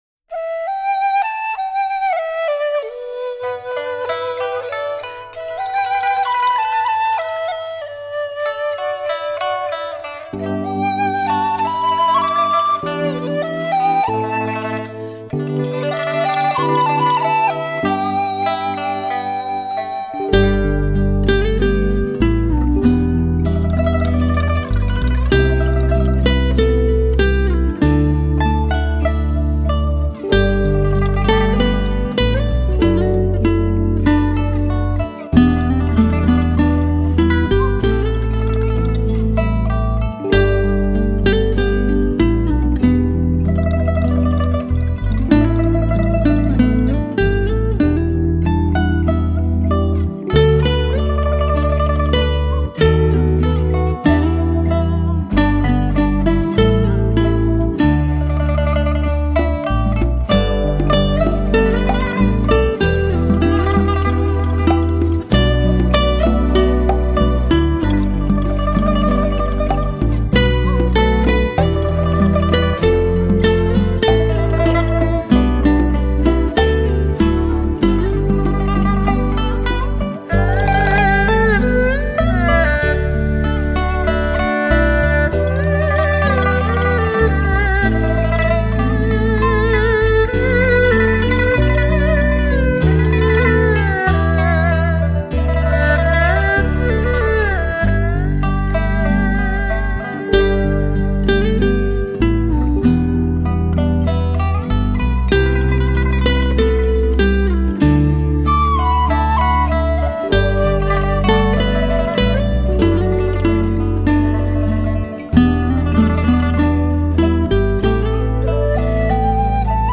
中国发烧民乐合辑
才能听得出二胡的哀怨，琵琶的婉转，古筝的激越……恰如潮湿额雨巷，江畔锦衣夜行的女子以及悲伤思乡的游子。